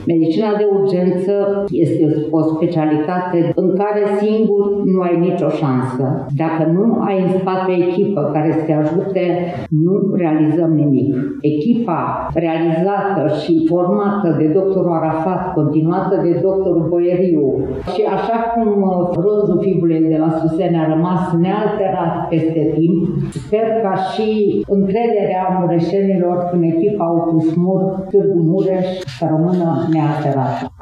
La ceremonia de astăzi din Sala de Oglinzi a Palatului Culturii din Tg. Mureș, de cea mai prestigioasă distincție a județului Mureș, “Fibula de la Suseni”, au beneficiat cinci medici de prestigiu, o asistentă medicală și o studentă la medicină militară.